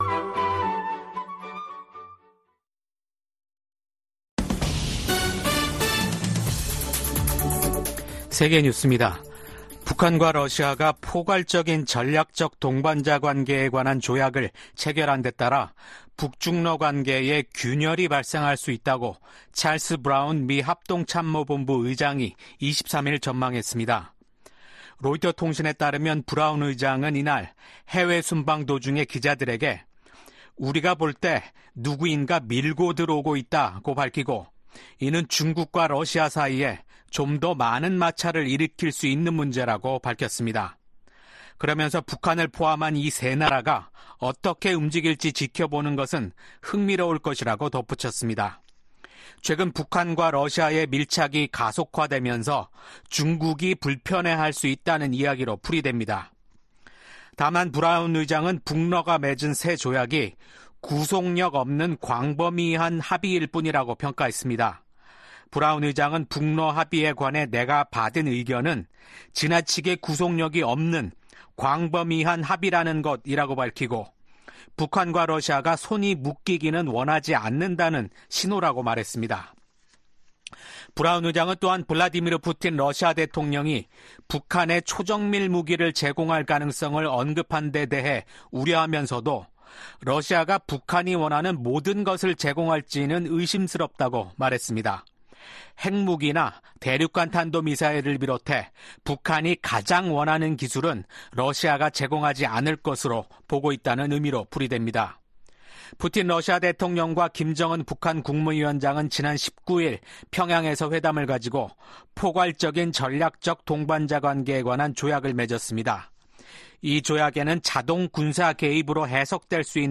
VOA 한국어 아침 뉴스 프로그램 '워싱턴 뉴스 광장' 2024년 6월 25일 방송입니다. 북한 동창리 서해위성발사장에 대규모 굴착 작업 흔적이 들어났습니다. 한국 대통령실은 러시아가 북한에 고도의 정밀무기를 제공하면 우크라이나에 무기를 지원하겠다고 경고했습니다. 미국과 한국 외교장관이 전화 통화를 갖고 북한과 러시아가 체결한 새 협정을 규탄했습니다.